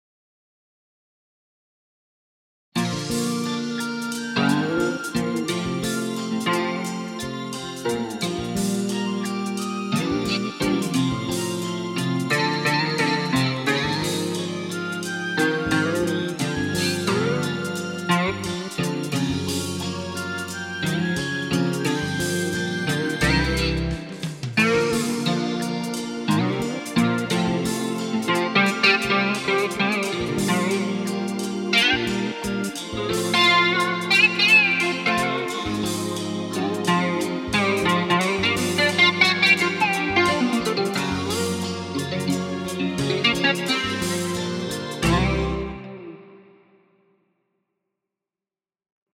I’ve created for you some examples of the described sliding imitation technique, combined with
some other tricks – like string bending imitation.